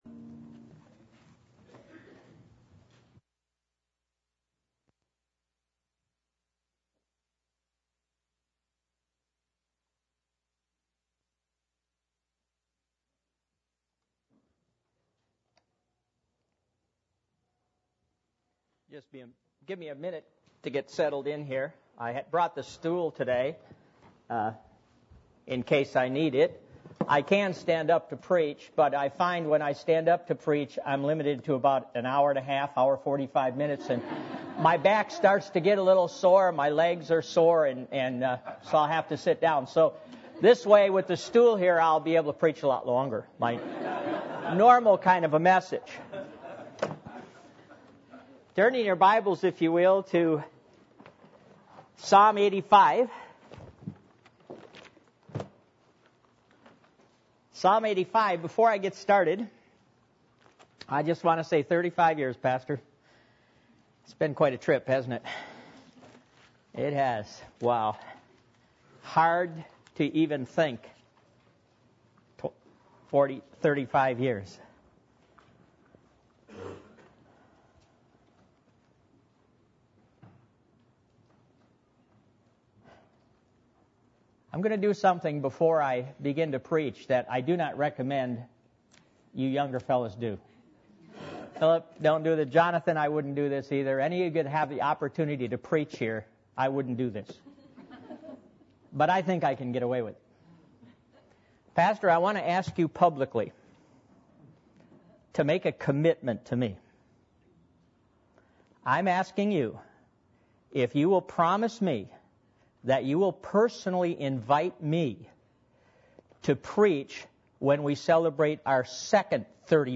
Passage: Psalm 85:1-13 Service Type: Sunday Morning